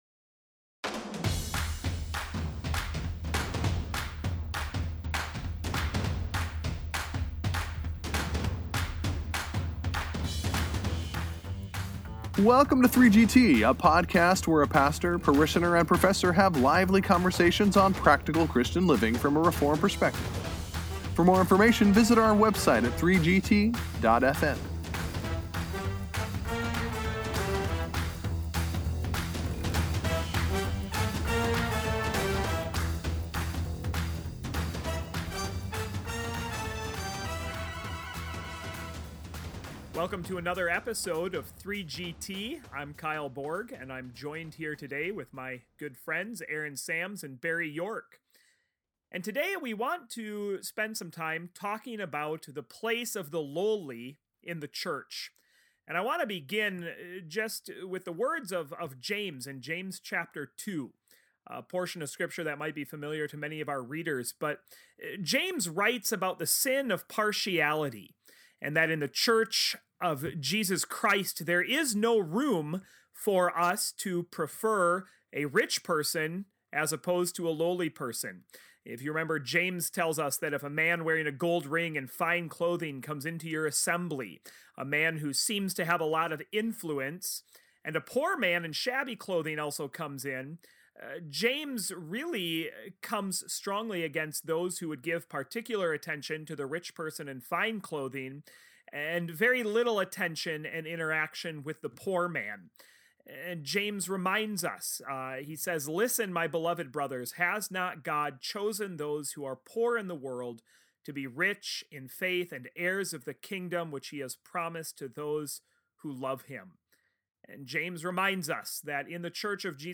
The Guys discuss how easy it is to forget the very ones God tells us to remember. Practical ways for the church to honor the lowly are offered.